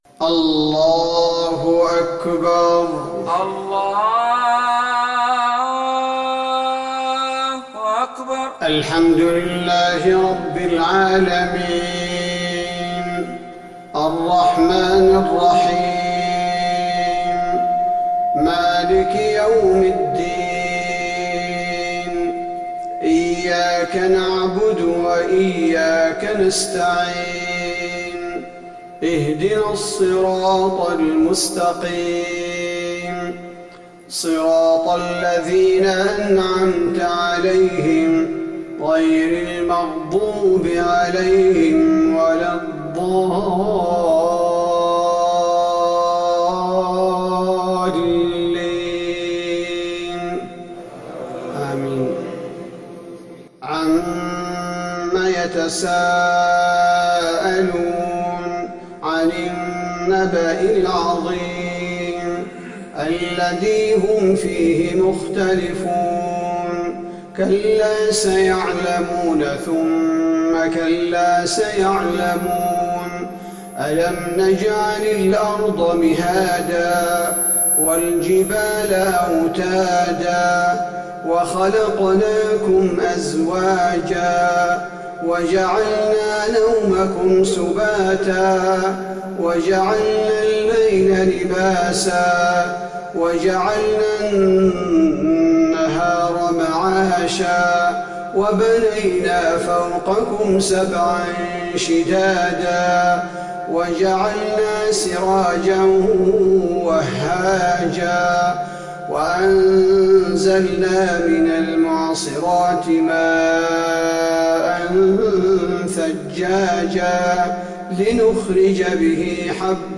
صلاة العشاء للقارئ عبدالباري الثبيتي 4 شعبان 1441 هـ
تِلَاوَات الْحَرَمَيْن .